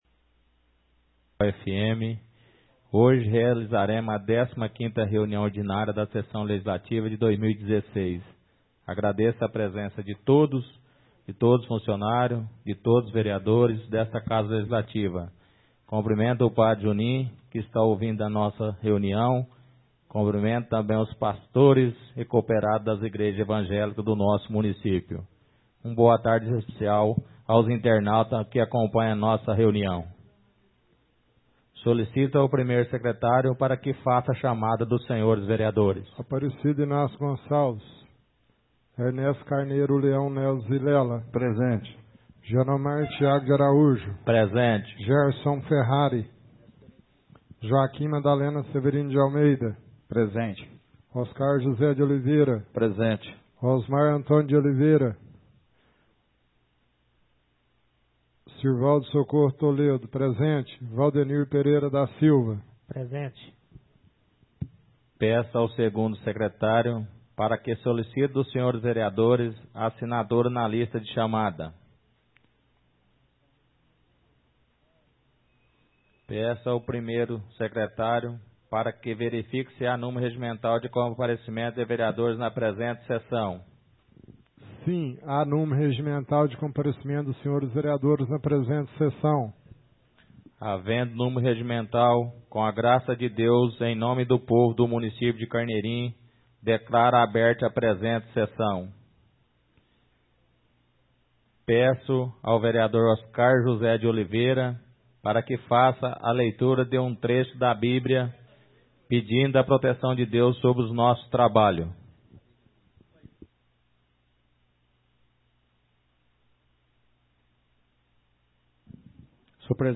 Áudio da 15ª reunião ordinária de 2016, realizada no dia 10 de Outubro de 2016, na sala de sessões da Câmara Municipal de Carneirinho, Estado de Minas Gerais.